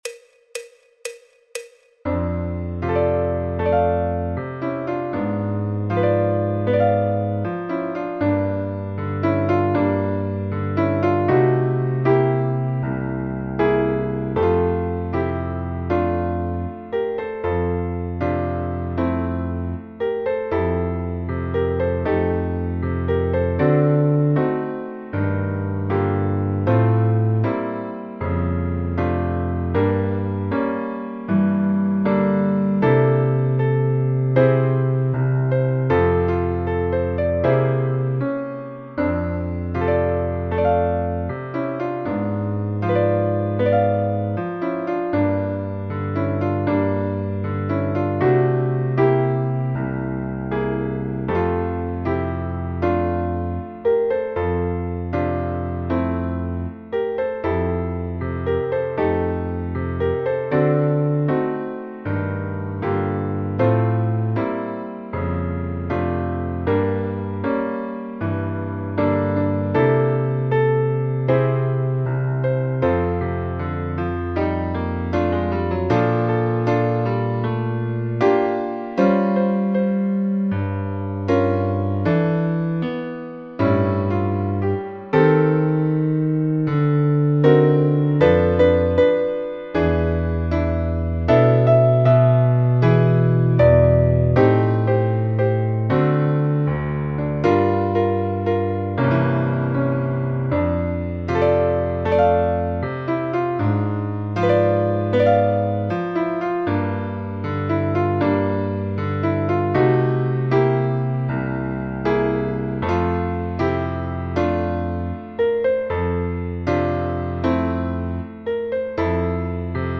El MIDI tiene la base instrumental de acompañamiento.
Fa Mayor
Jazz, Popular/Tradicional